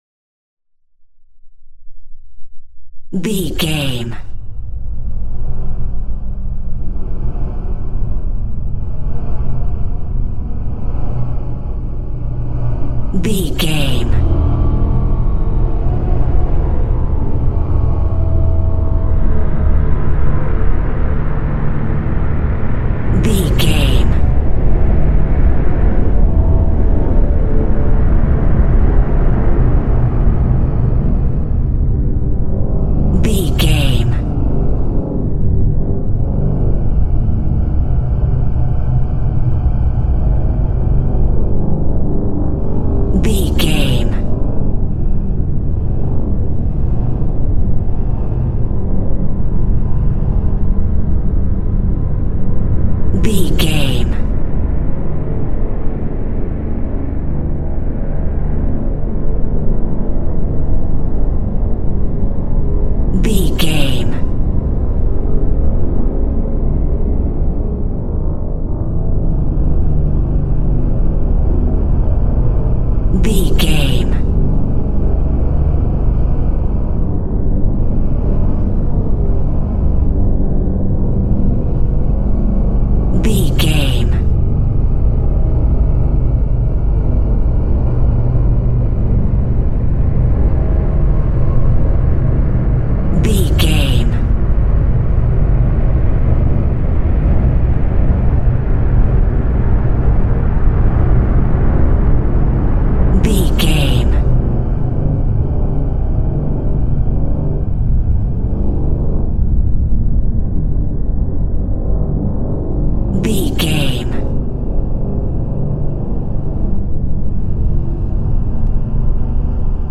Aeolian/Minor
Slow
scary
ominous
dark
suspense
eerie
horror
synth
keyboards
ambience
pads
eletronic